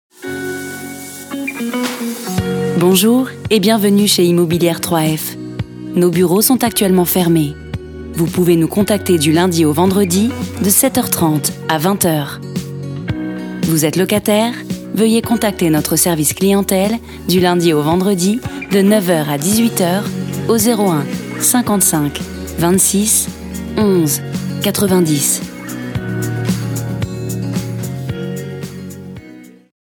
Attente et répondeur téléphonique pour le compte de Immobilière 3F, premier bailleur social français.
Immobiliere_3F_Repondeur.mp3